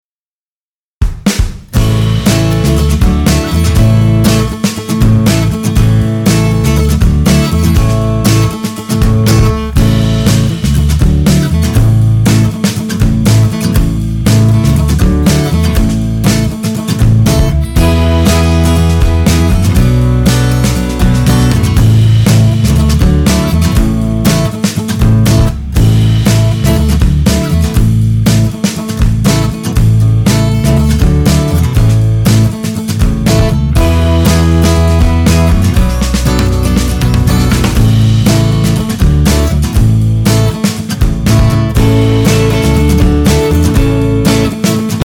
Downloadable Instrumental Track